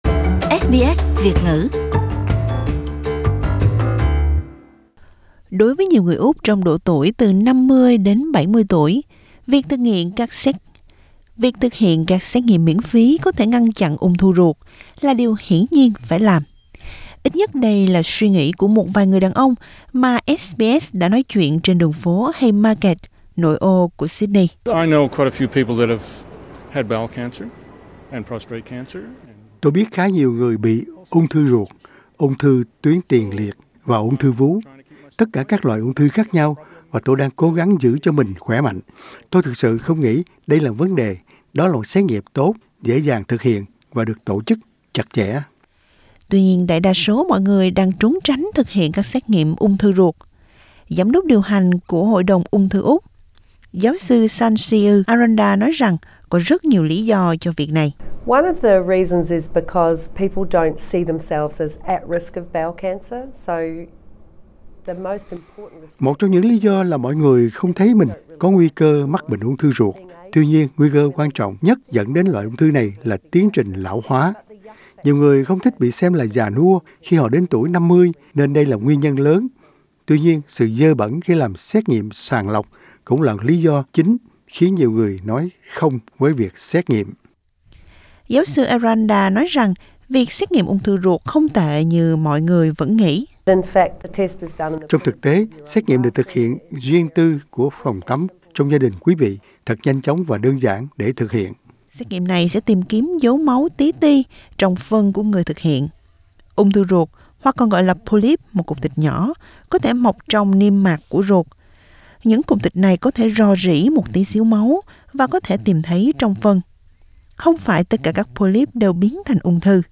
Ít nhất đây là suy nghĩ của một vài người đàn ông mà SBS đã phỏng vấn trên đường phố Haymarket, vùng nội ô của Sydney.